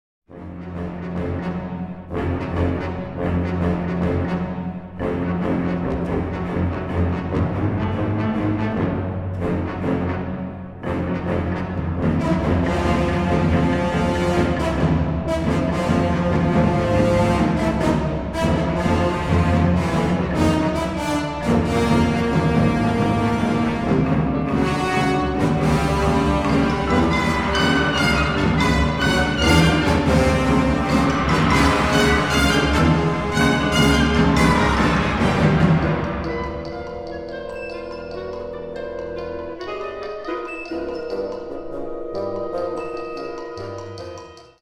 Unterkategorie Zeitgenössische Bläsermusik (1945-heute)
Besetzung Ha (Blasorchester)